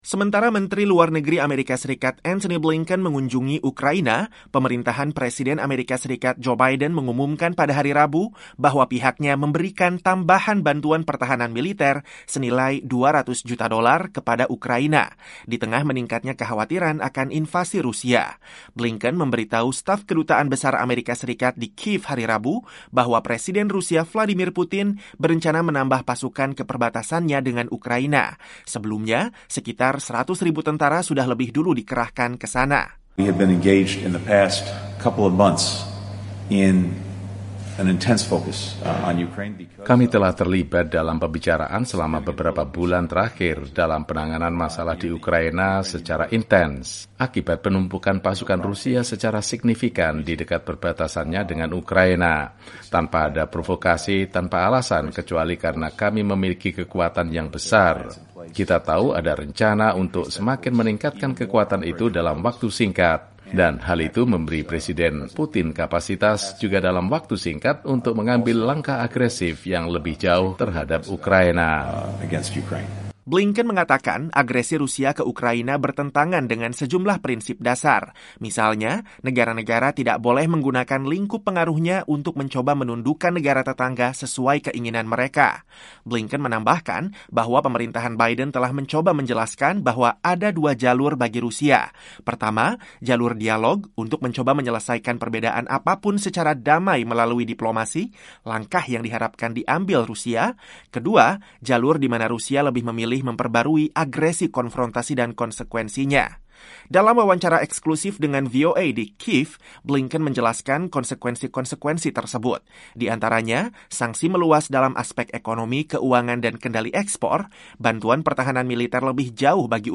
Dalam wawancara dengan VOA di tengah kunjungannya ke Kyiv, Ukraina, Menteri Luar Negeri AS Antony Blinken menegaskan bahwa konsekuensi besar yang lebih buruk dari yang pernah diberikan sebelumnya menanti Rusia, apabila negeri beruang merah itu menginvasi Ukraina.